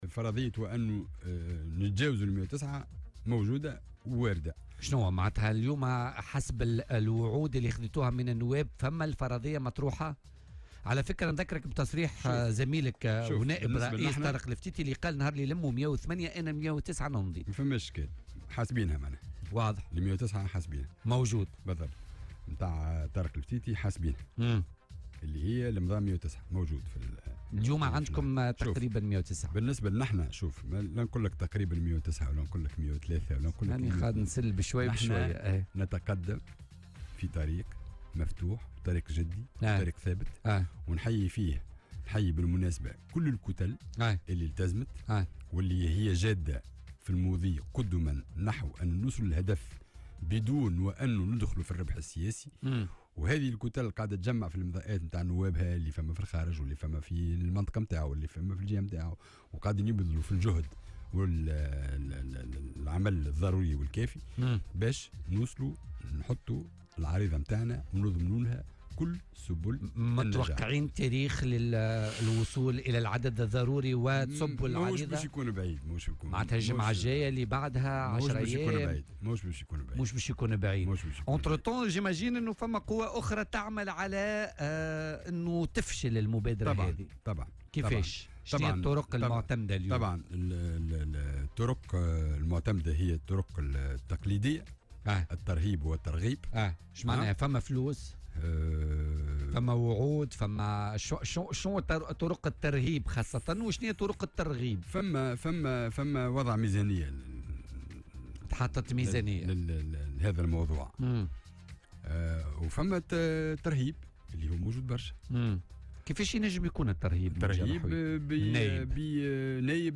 وقال في مداخلة له اليوم في برنامج "بوليتيكا" إن فرضية تجاوز الـ109 أصوات المُستوجبة لسحب الثقة وارد جدّا، مشيرا أيضا إلى أنه قد يتم إيداع العريضة خلال الأيام القليلة القادمة.